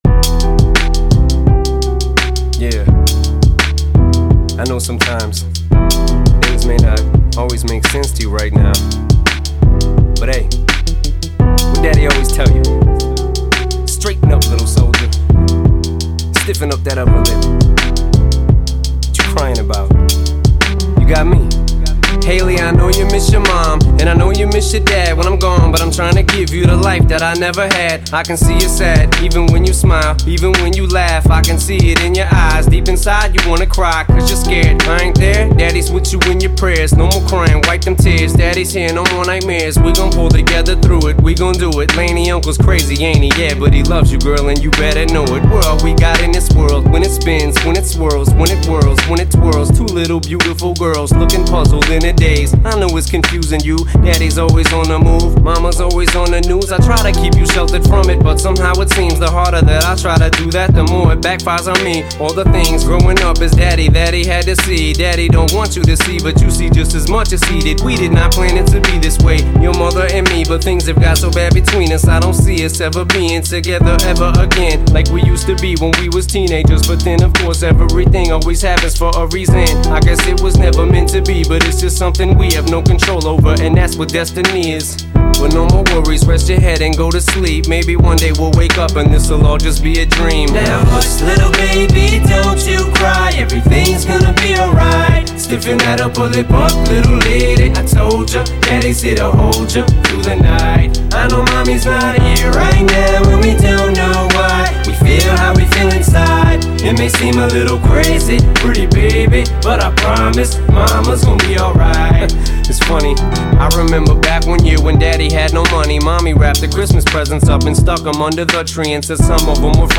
Реп/Rap [10]